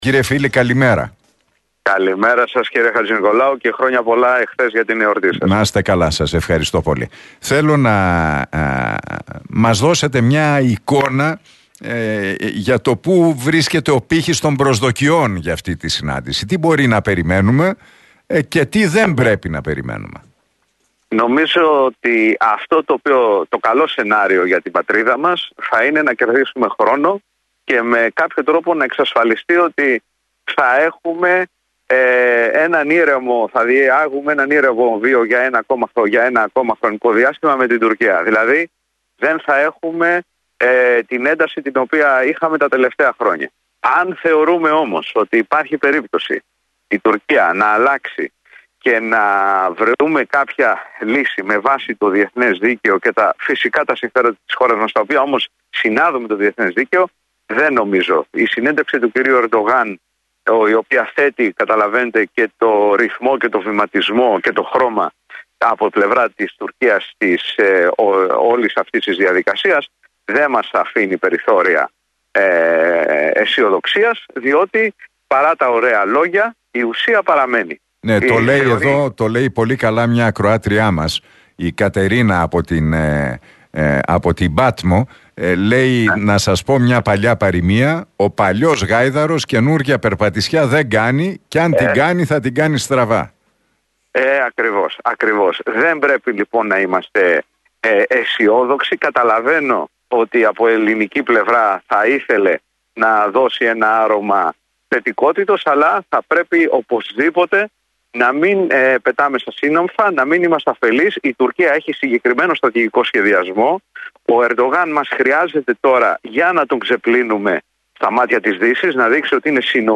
σε συνέντευξή του στον Realfm 97,8